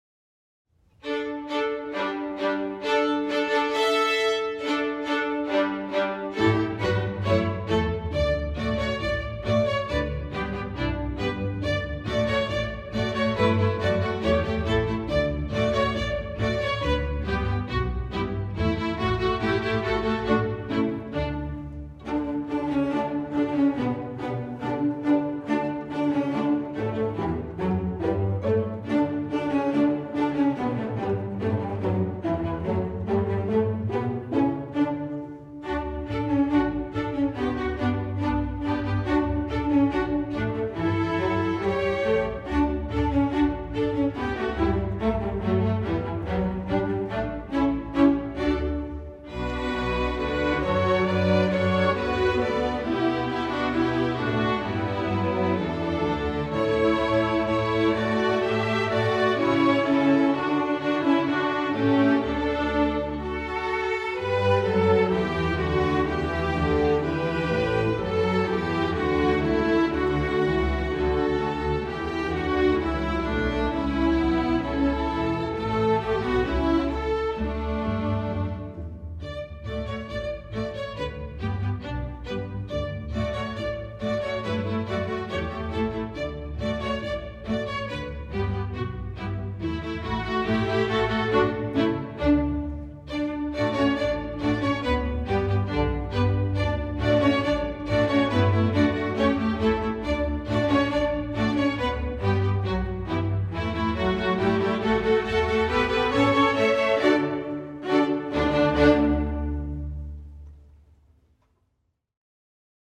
Instrumental Orchestra String Orchestra
String Orchestra